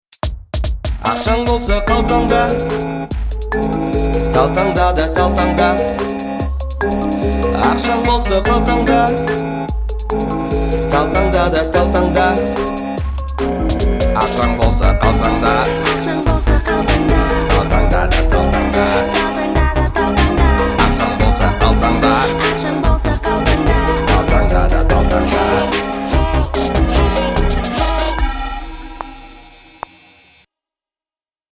• Качество: 320, Stereo
веселые
Веселая песня про деньги